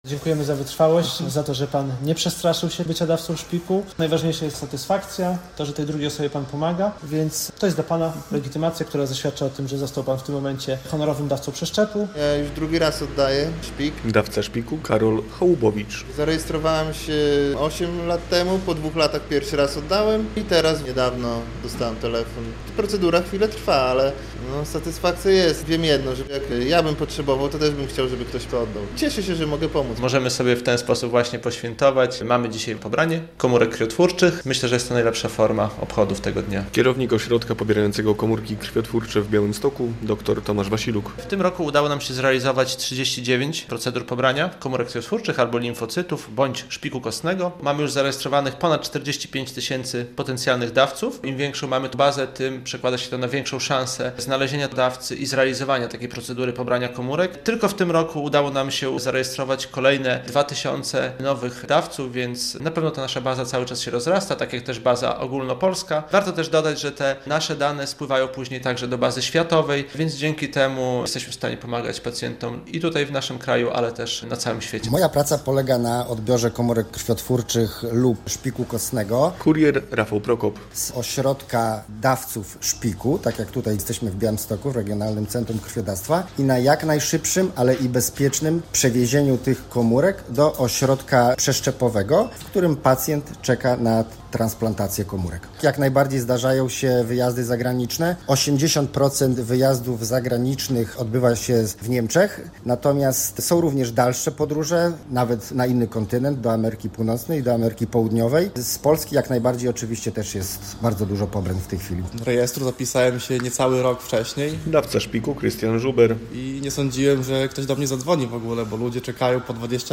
Ogólnopolski Dzień Dawcy Szpiku w Regionalnym Centrum Krwiodawstwa i Krwiolecznictwa w Białymstoku - relacja